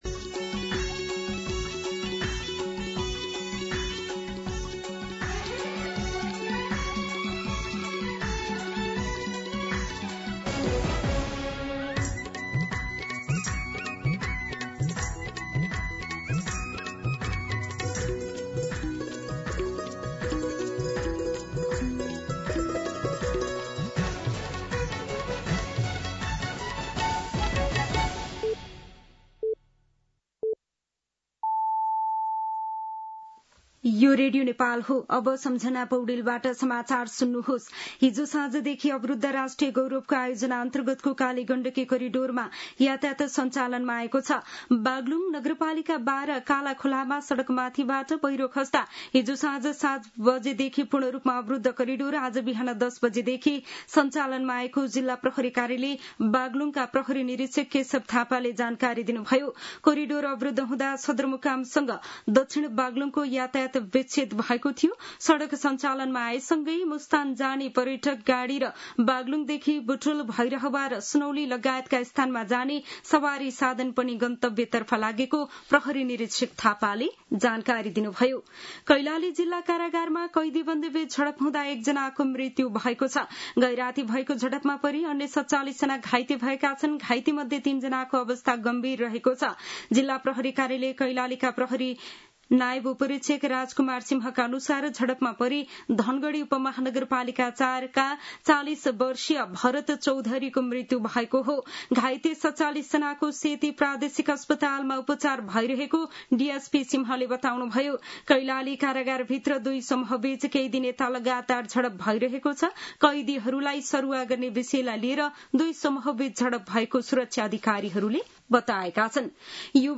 An online outlet of Nepal's national radio broadcaster
दिउँसो १ बजेको नेपाली समाचार : २४ साउन , २०८२